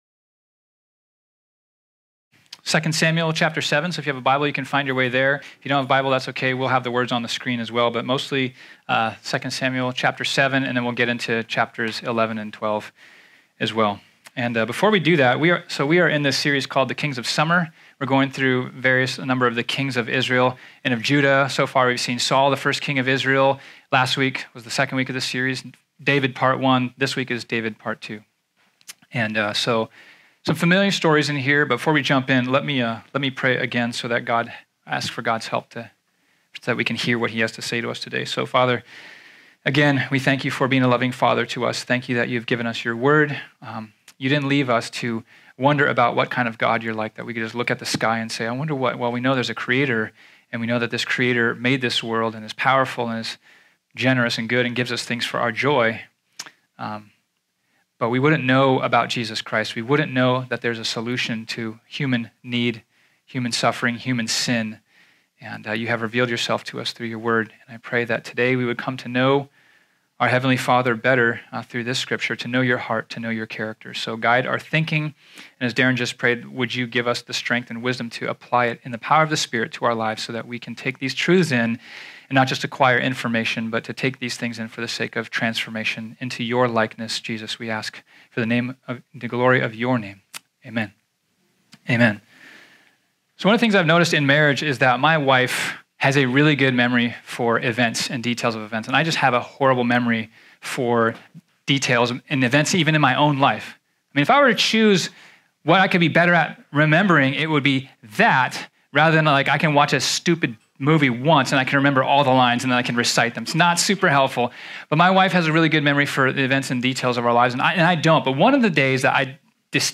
This sermon was originally preached on Sunday, June 10, 2018.